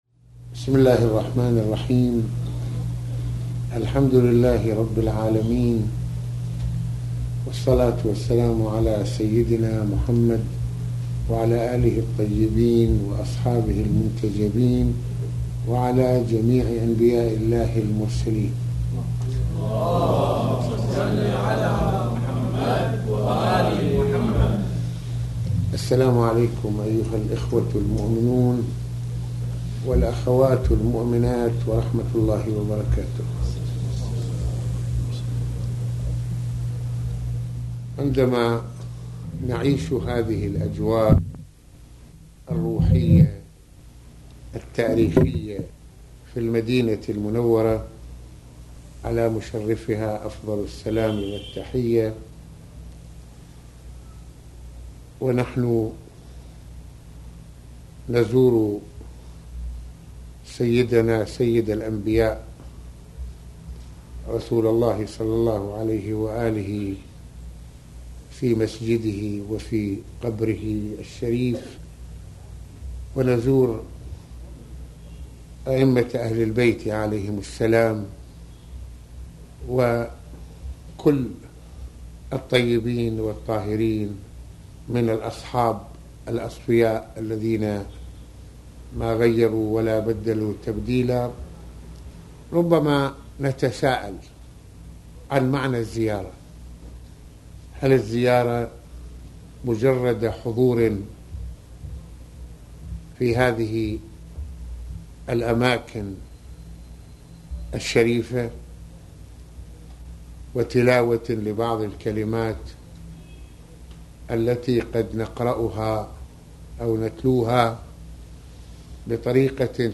- تحدث العلامة المرجع السيد محمد حسين فضل الله في هذه المحاضرة عن معاني زيارة رسول الله (ص)، بأن نجدد ونعيش معهم إلاسلام، فنتحمل مسؤولية تبليغ الرسالة ولو بنسبة قليلة